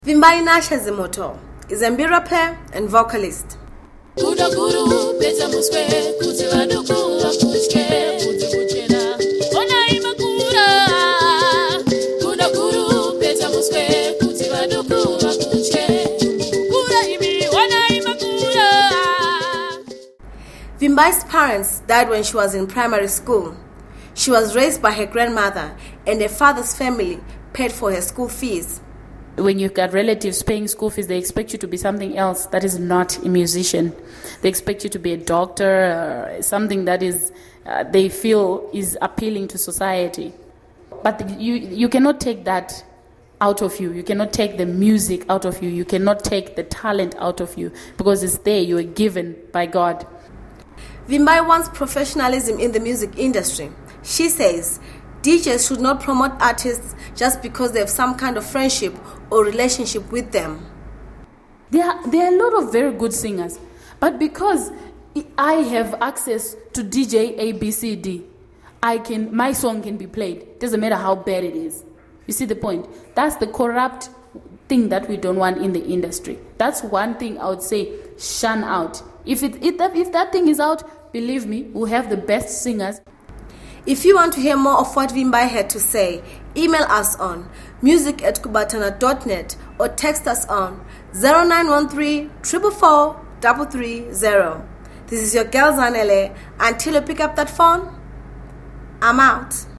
an mbira player and vocalist